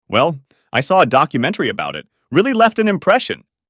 このブログ記事では、話題性の高いニュースTopicに関する会話テキストを元に、アメリカ英語の自然な口語を学びます。